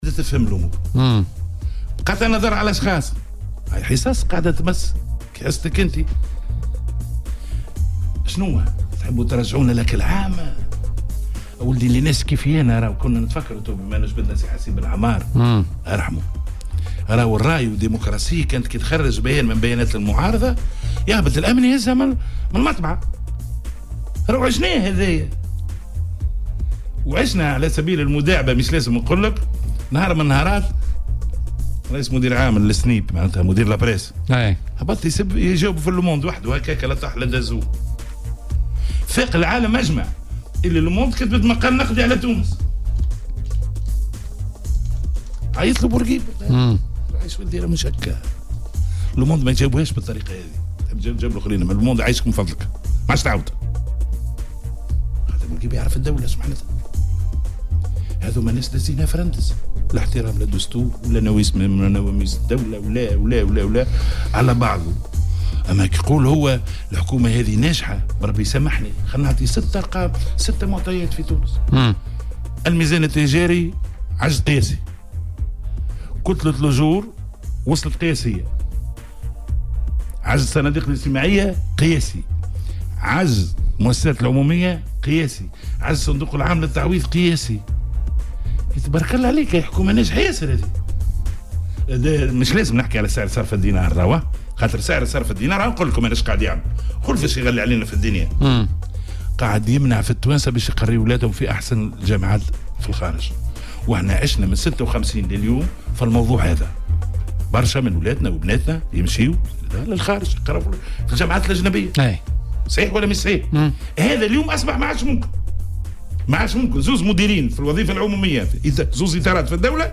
واعتبر ضيف "بوليتيكا" على "الجوهرة أف ام" أن مثل هذه الاجراءات تضييقا على حرية التعبير.